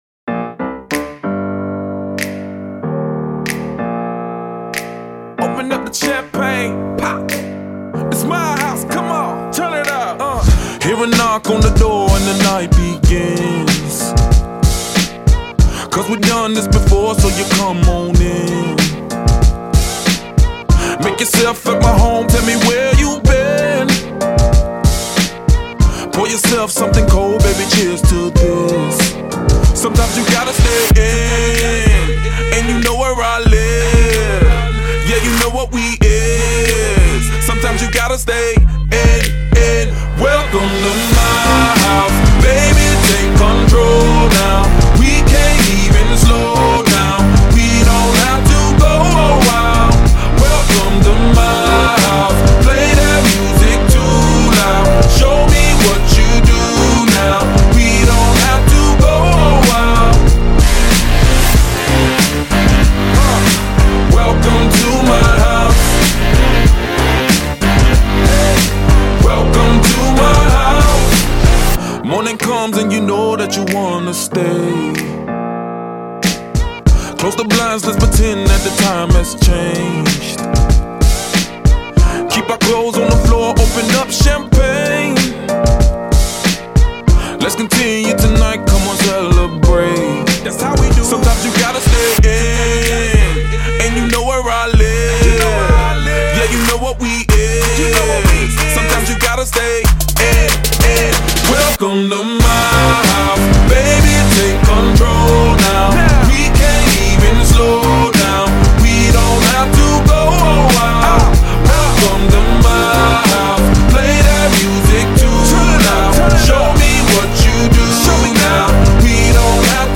Pop 2010er